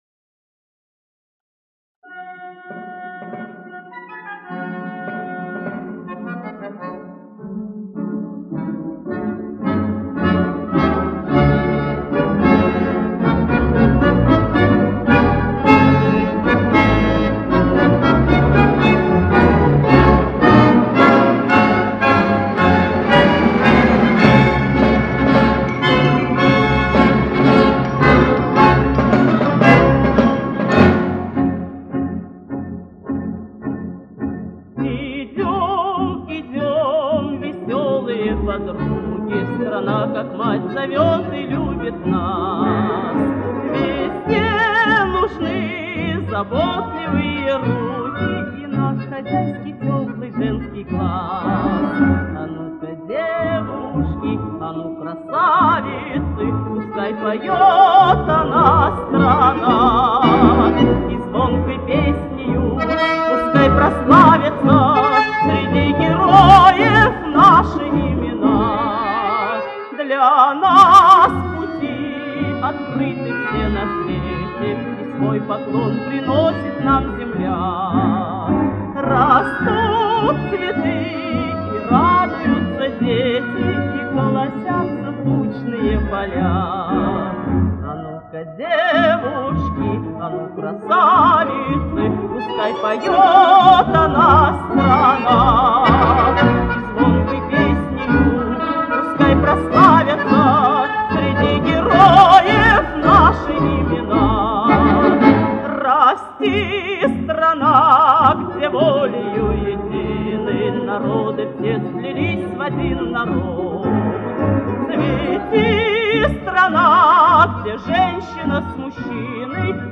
Марши
Не самое удачное исполнение